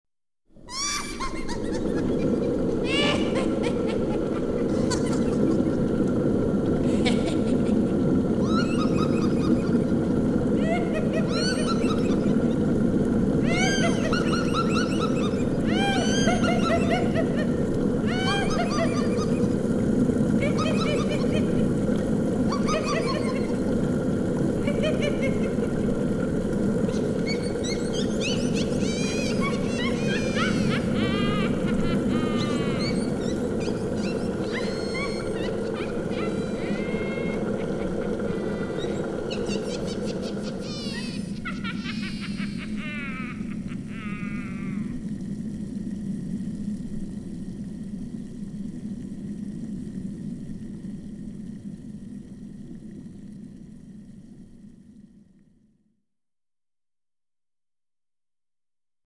Scary Sounds - 62 - Wicked Witches Type